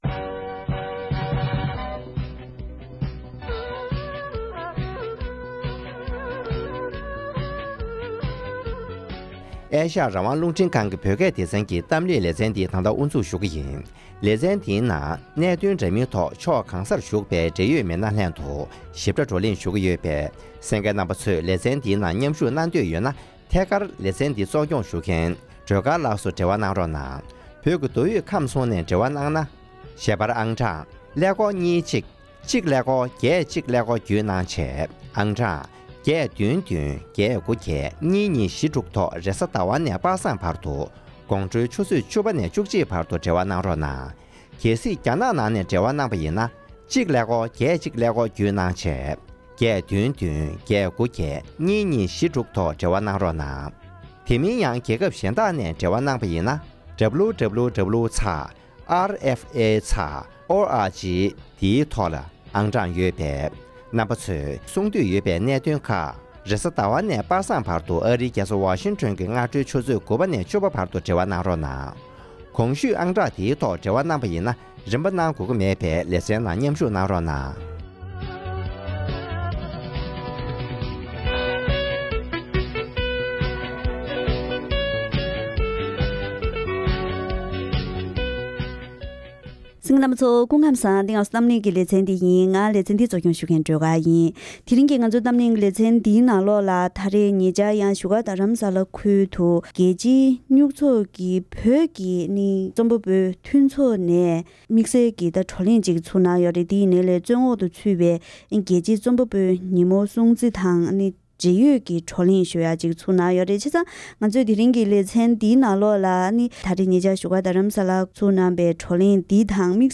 བོད་ཀྱི་རྩོམ་པ་པོའི་མཐུན་ཚོགས་ཀྱི་བགྲོ་གླེང་།